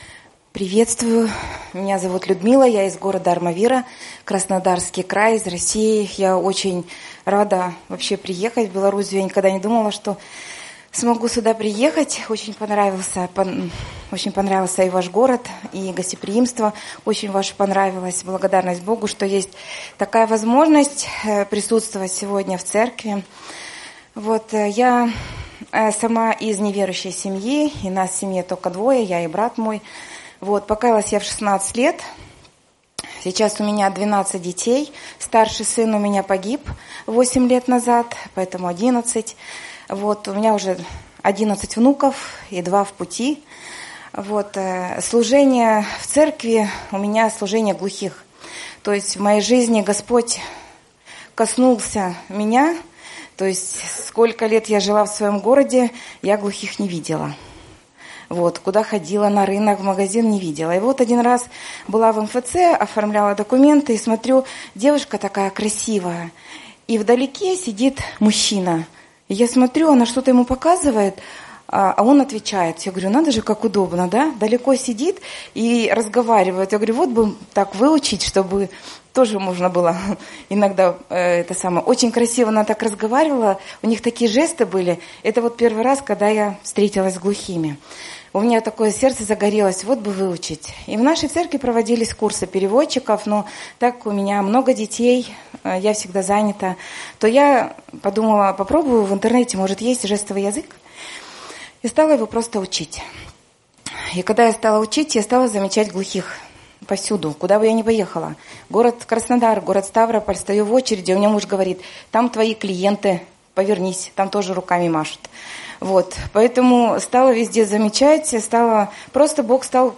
Брат свидетельствует о своём детстве, когда церковь ещё не была такой свободной, как сейчас, но при этом переживала обильное действие Духа Святого.